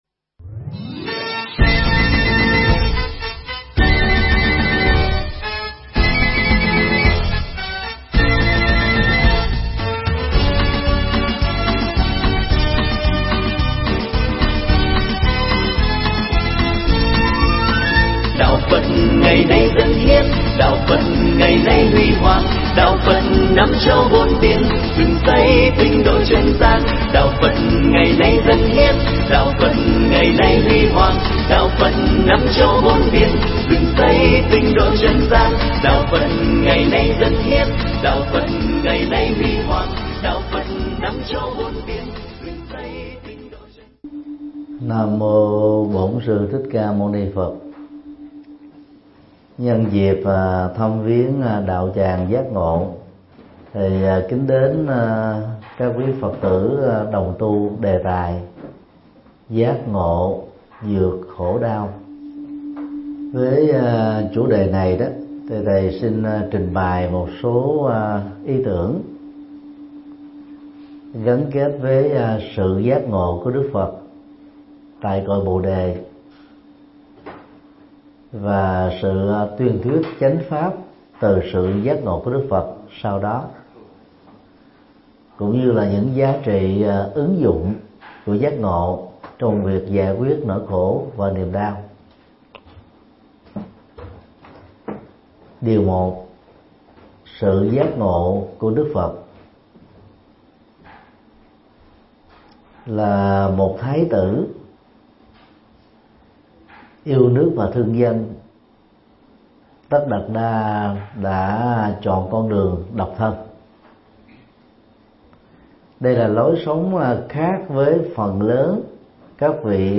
Mp3 Thuyết Pháp Giác Ngộ Thoát Khổ Đau – Thượng Tọa Thích Nhật Từ giảng tại đạo tràng Giác Ngộ (Hoa Kỳ), ngày 30 tháng 5 năm 2017